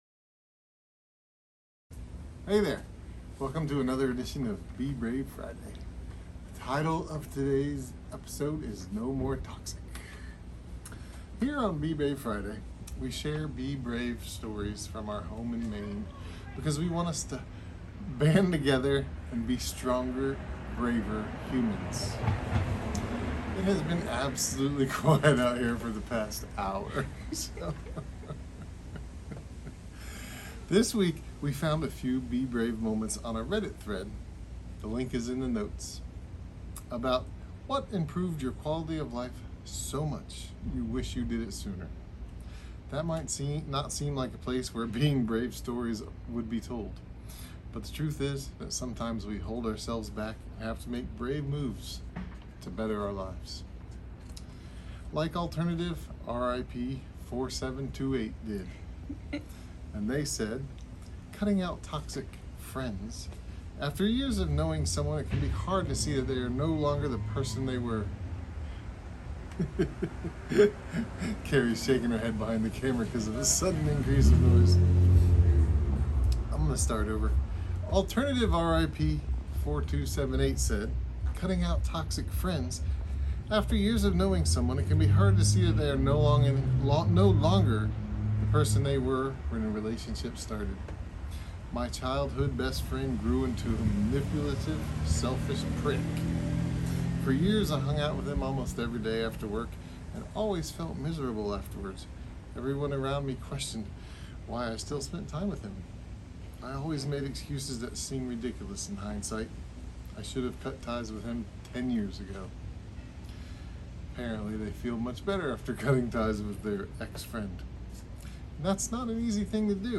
Here on Be Brave Friday we share Be Brave stories from our home in Maine because we want us all to ban together and be stronger, braver humans.